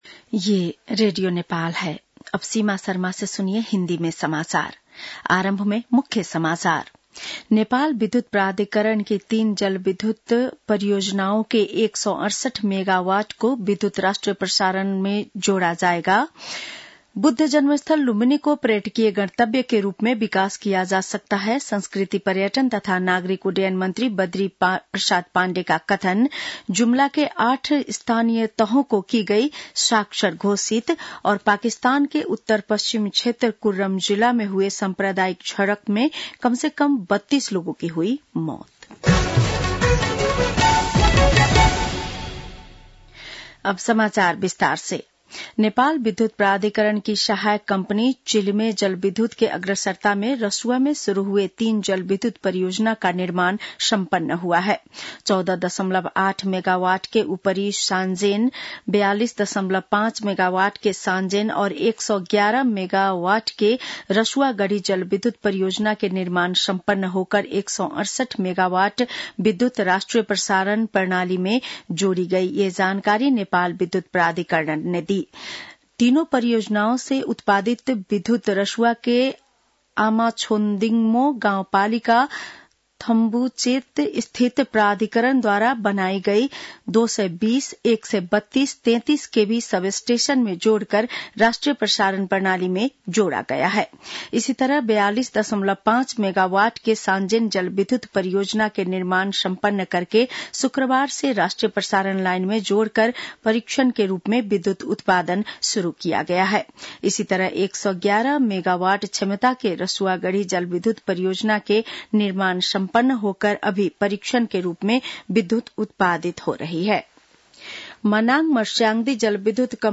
बेलुकी १० बजेको हिन्दी समाचार : ९ मंसिर , २०८१